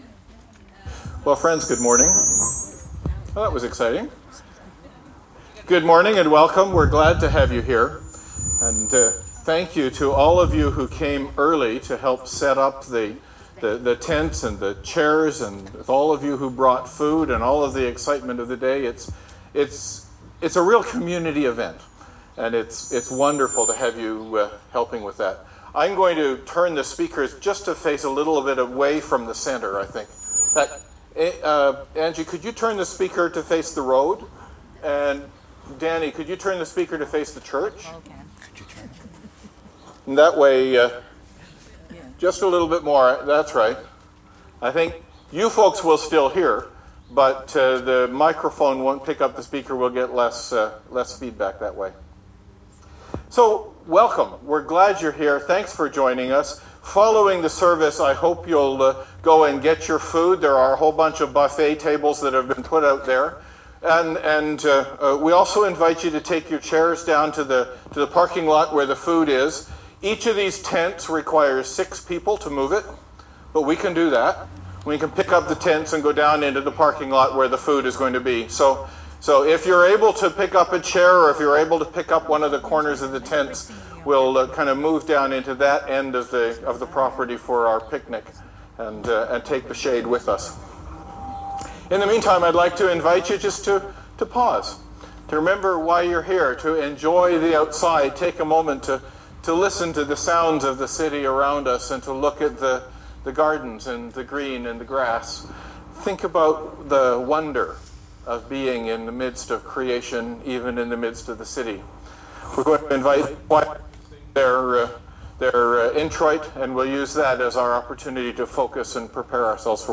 Outdoor Service June 16, 2019 Wonder and Awe Psalm 8 Romans 5: 1-5 Because we were worshipping outdoors this week, we were unable to livestream to YouTube.